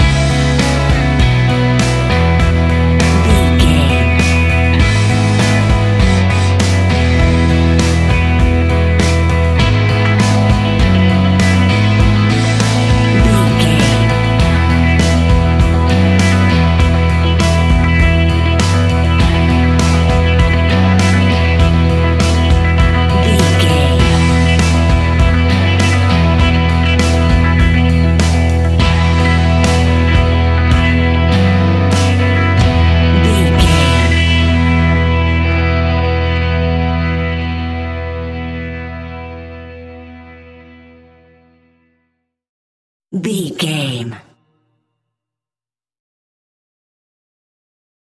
Ionian/Major
indie pop
fun
energetic
uplifting
upbeat
rocking
groovy
guitars
bass
drums
piano
organ